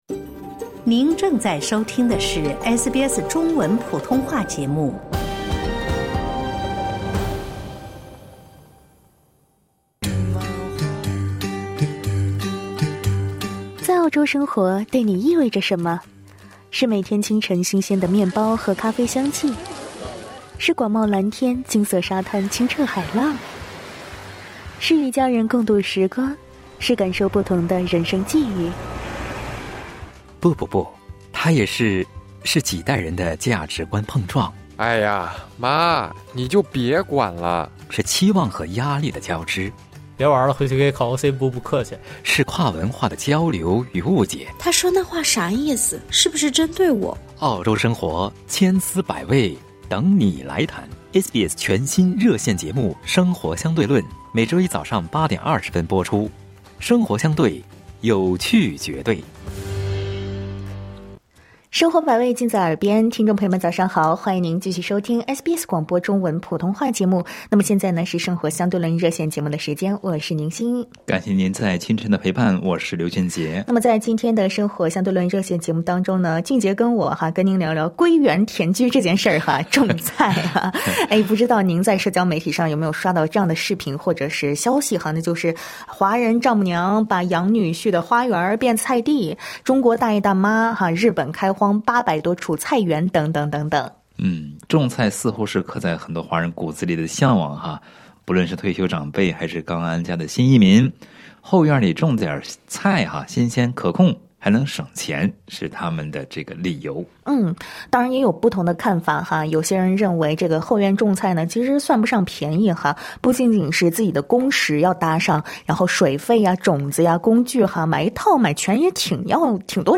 《生活相对论》热线节目 每周一早晨8:30在SBS普通话电台播出。